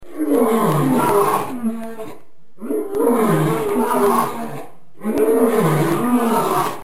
دانلود صدای نعره شیر نر از ساعد نیوز با لینک مستقیم و کیفیت بالا
جلوه های صوتی